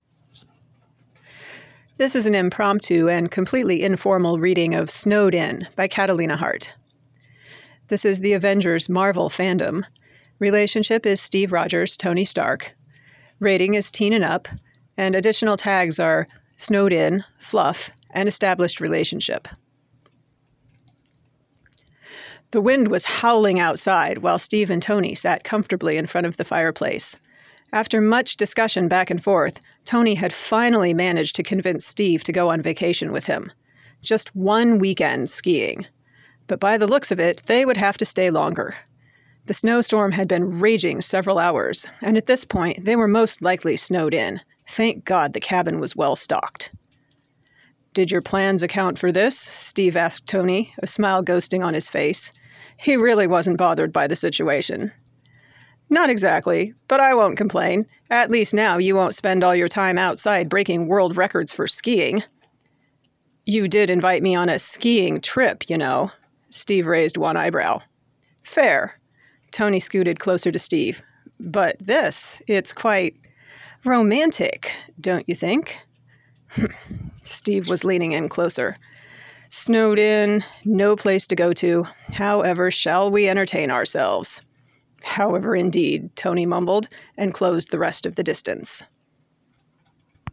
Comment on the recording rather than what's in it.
It's straight into my phone, with the birds chirping in the background, and zero editing. Not exactly high quality podfic, here, but hopefully a fun little something to bring a smile.